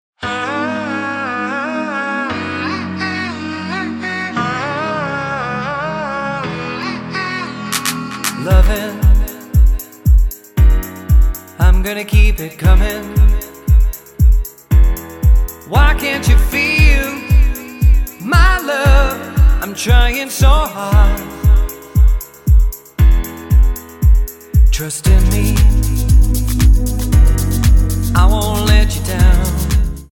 --> MP3 Demo abspielen...
Tonart:Abm Multifile (kein Sofortdownload.
Die besten Playbacks Instrumentals und Karaoke Versionen .